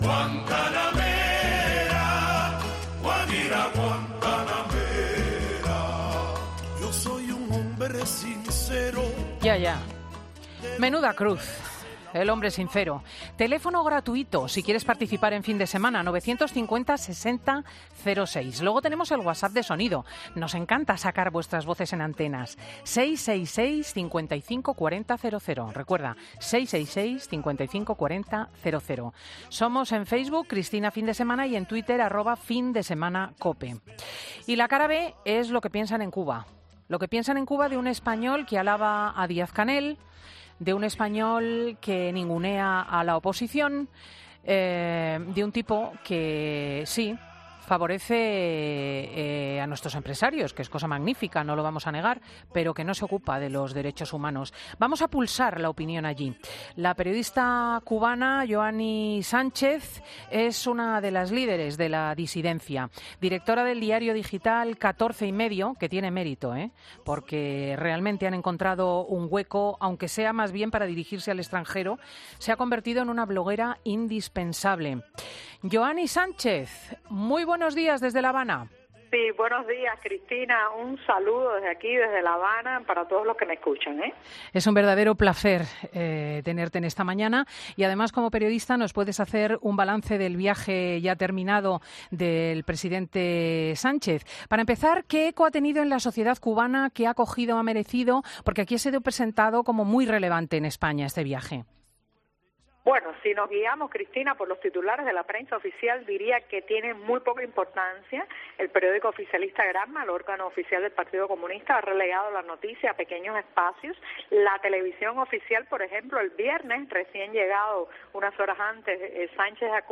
Lo ha explicado en 'Fin de Semana' la periodista y opositora cubana Yoani Sánchez.